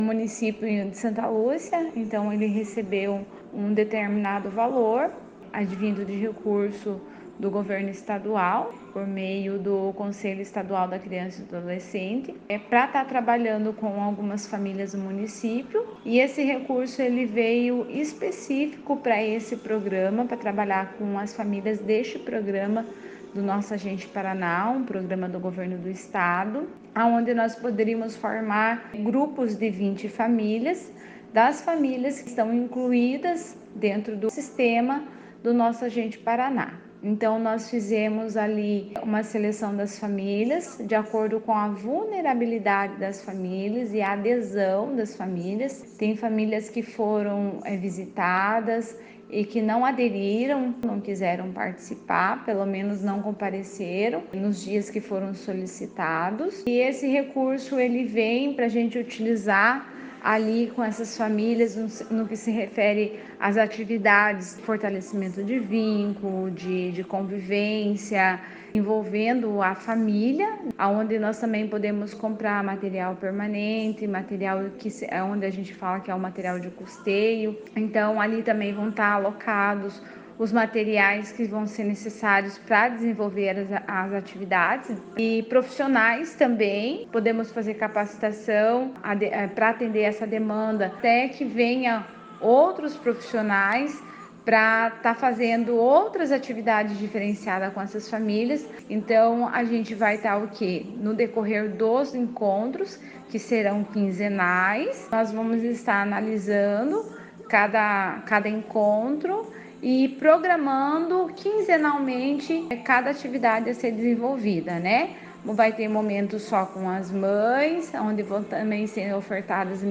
Sonora da Assistente Social e Coordenadora do CRAS